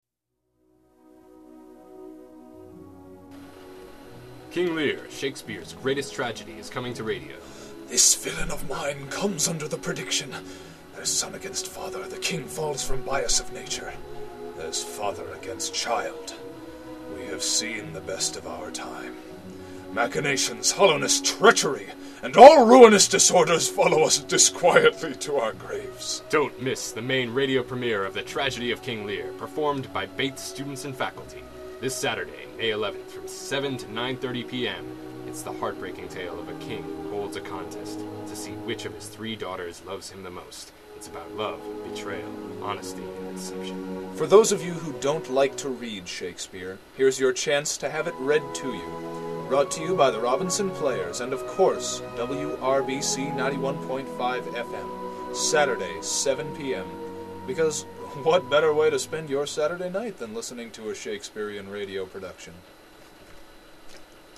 The Promo